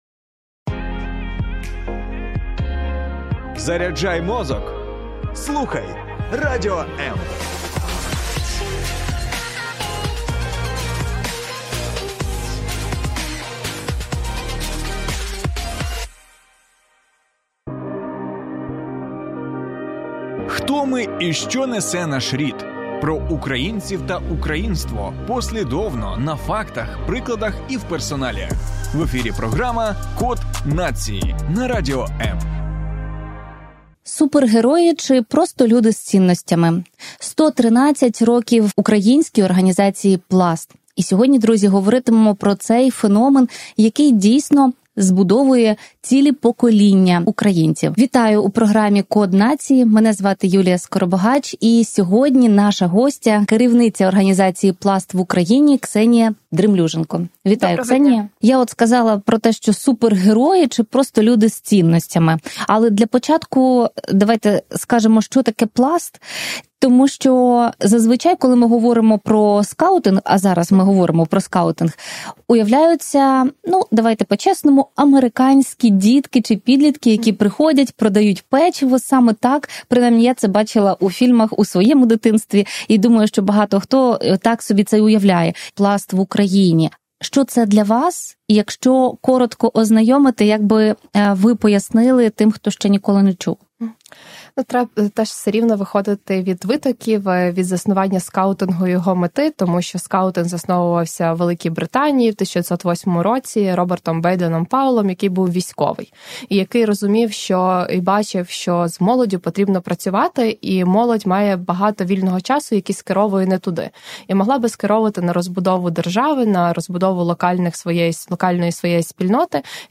Завантажати запис ефіру на тему: Пласт - виховання незламних